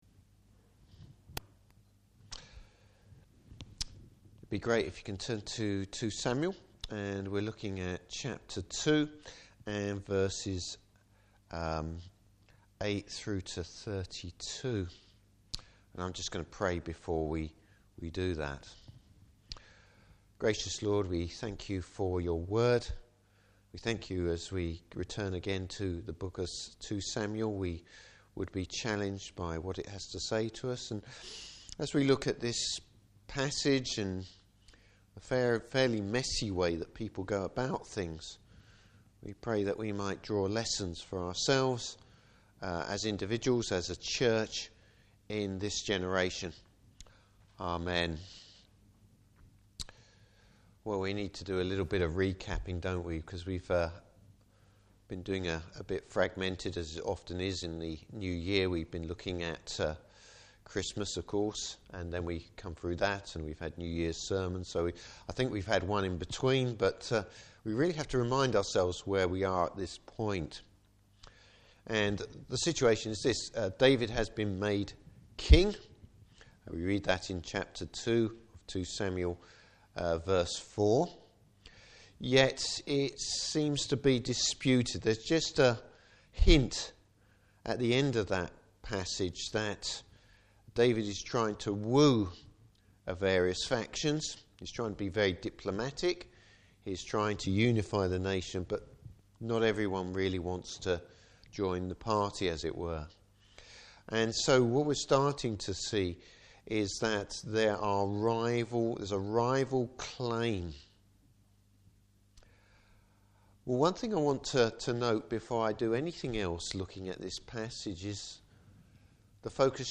Passage: 2 Samuel 2:8-32. Service Type: Evening Service Man’s sin!